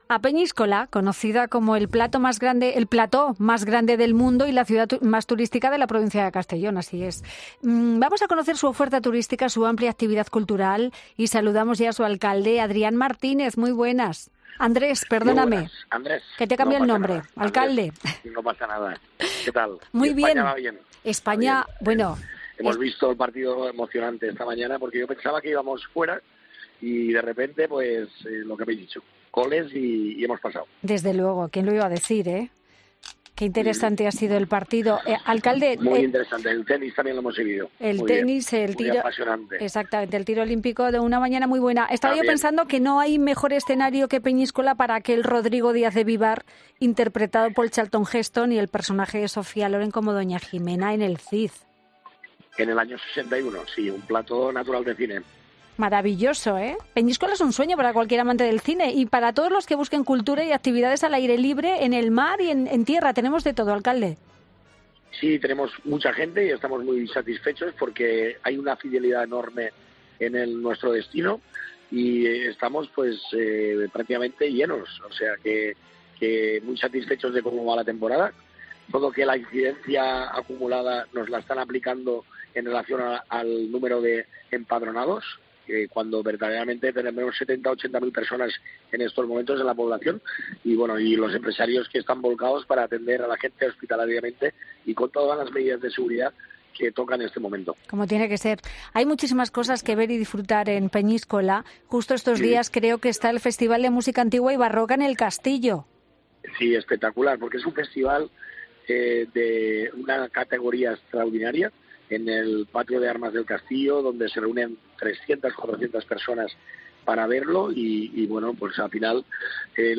El alcalde de Peñíscola ha pasado por los micrófonos de 'Fin de Semana' para dar a conocer la amplia variedad turística de la ciudad